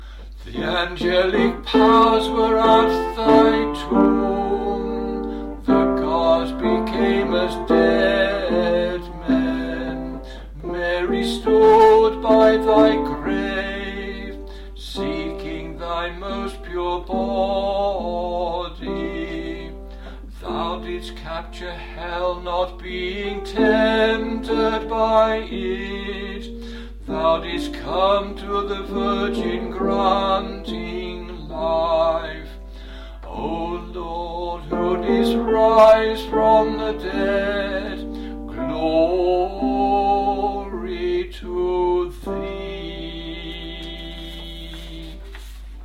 TONE 6 TROPARION
tone-6-troparion.mp3